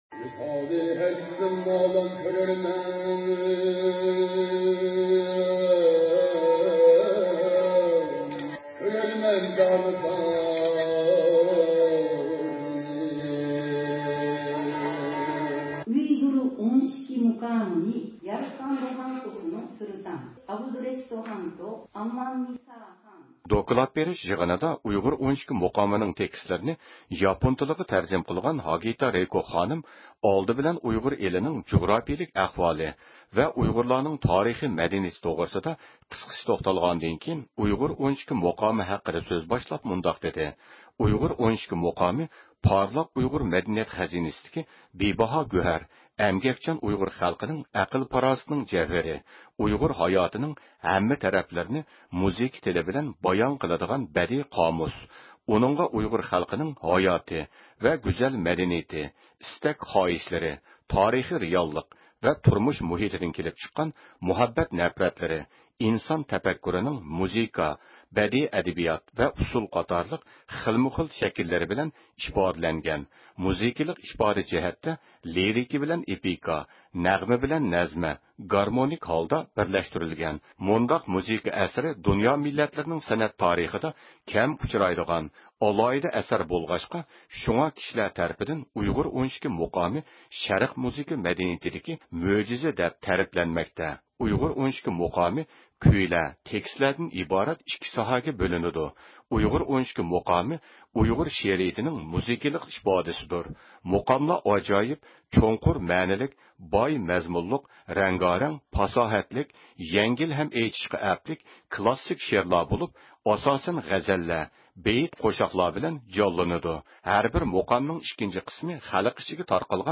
ئۇ ھاياجانلانغان ھالدا ئۆز تەسىراتىنى بايان قىلىپ ئۆتتى.